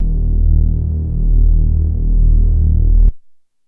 D#_07_Bass_01_SP.wav